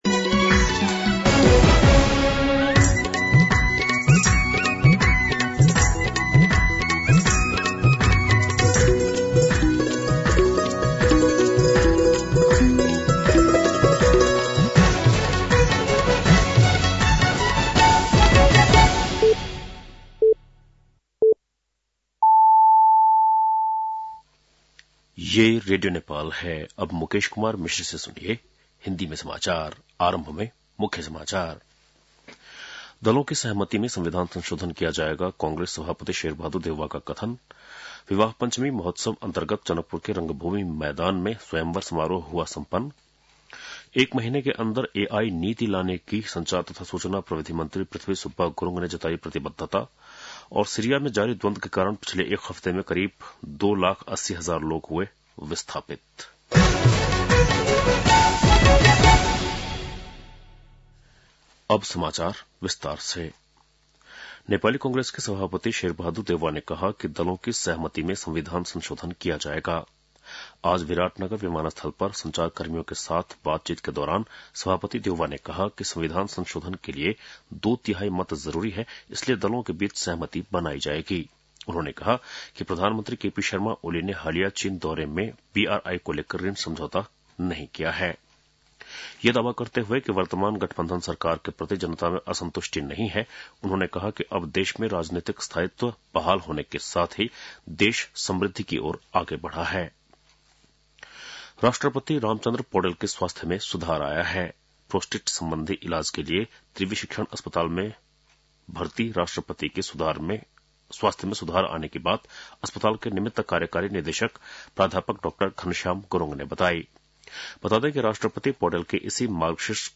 बेलुकी १० बजेको हिन्दी समाचार : २२ मंसिर , २०८१
10-PM-Hindi-News-8-21.mp3